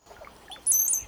Sounds from the IBM Glen
Brown-headed Cowbird.wav